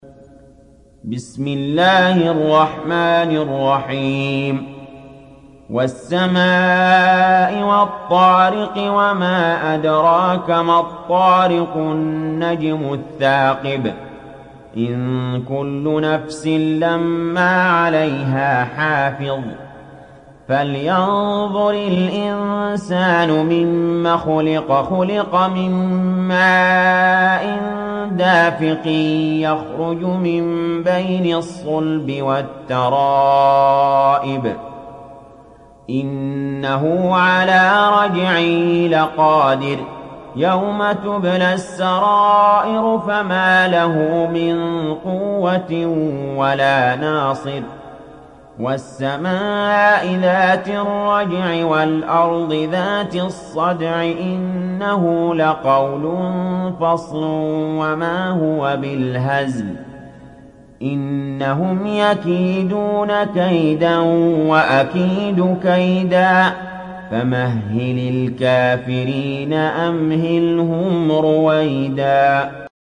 تحميل سورة الطارق mp3 بصوت علي جابر برواية حفص عن عاصم, تحميل استماع القرآن الكريم على الجوال mp3 كاملا بروابط مباشرة وسريعة